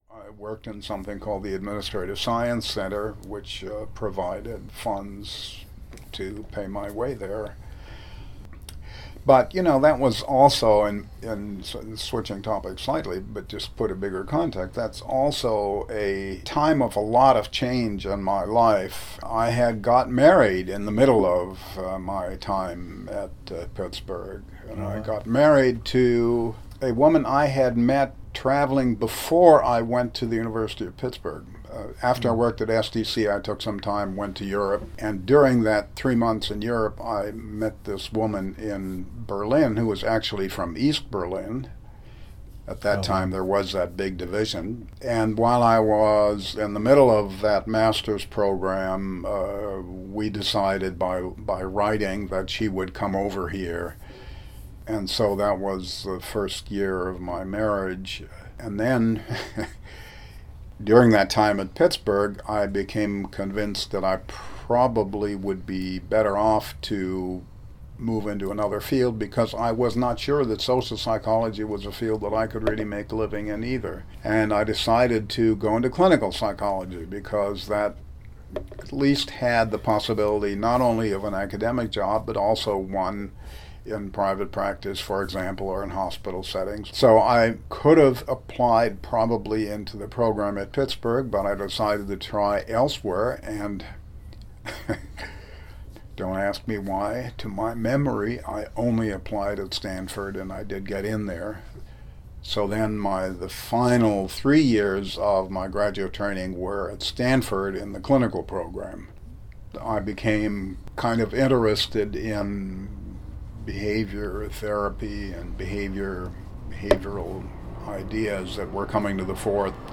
Dr. Bentler recalls in this next excerpt how he started to find his academic interests and his journey from college to grad school:
Dr. Bentler recalls moving into clinical Psychology and starting at Stanford University in pursuit of this degree.